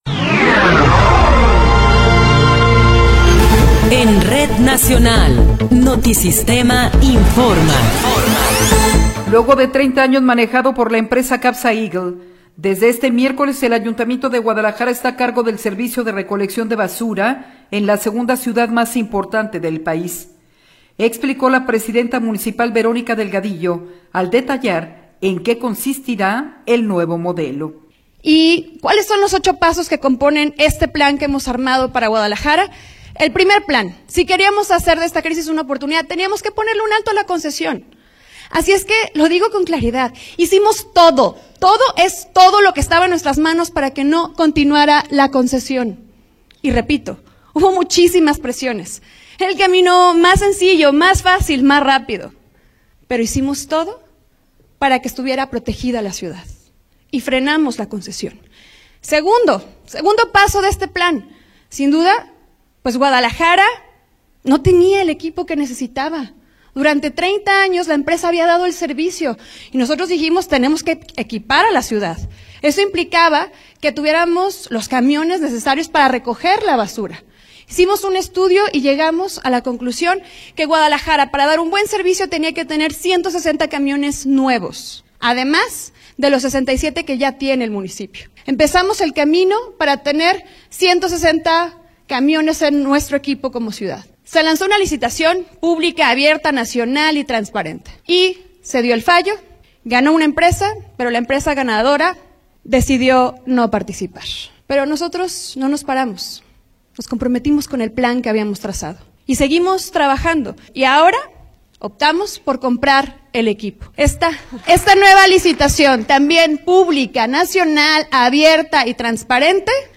Noticiero 21 hrs. – 22 de Diciembre de 2024